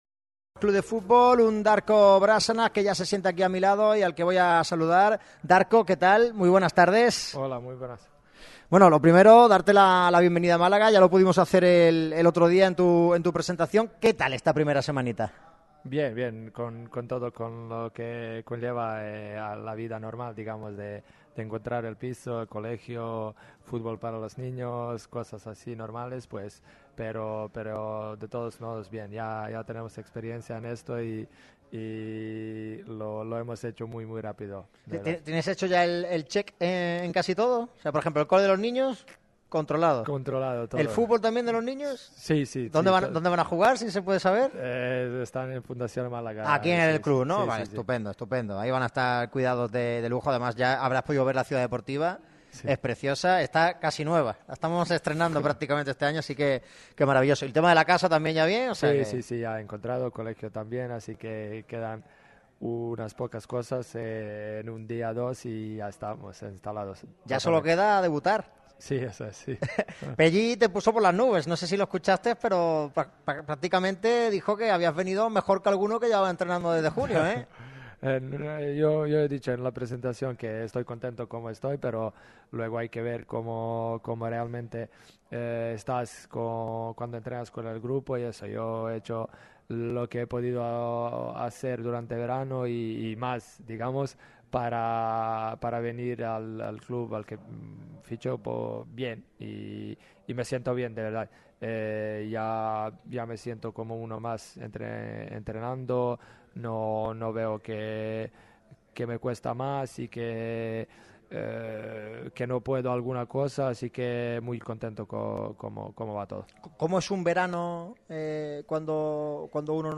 Lee y escucha la interesantísima entrevista del centrocampista serbio.
Darko Brašanac ha ofrecido sus primeras palabras como jugador malaguista en Radio MARCA Málaga y ha dejado una charla interesantísima.